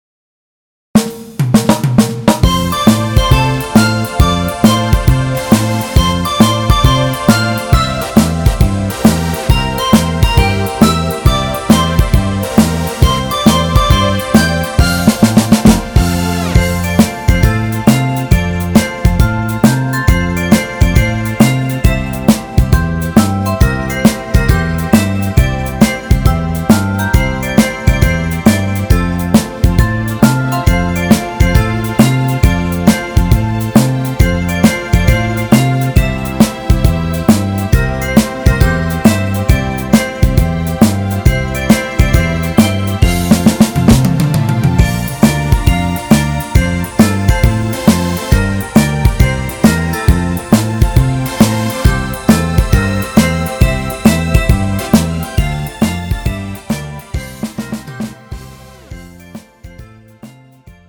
음정 F 키
장르 가요 구분 Pro MR